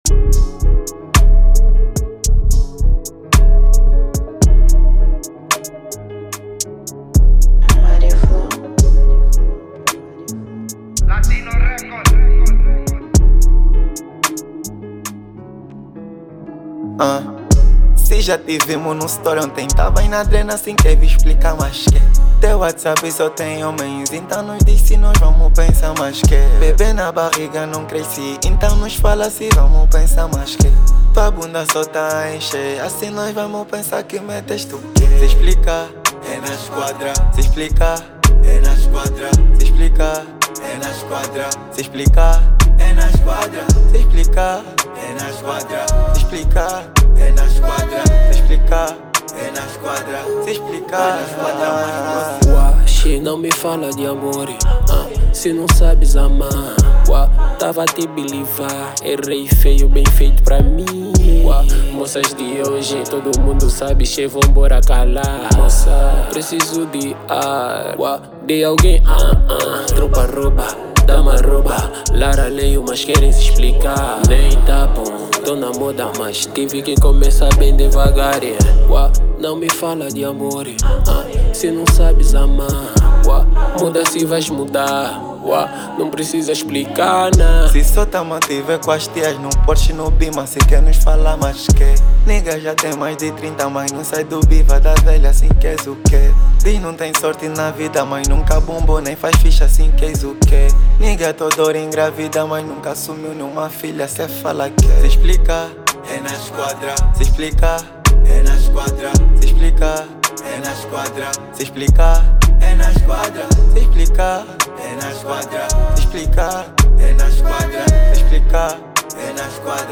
Categoria: Rap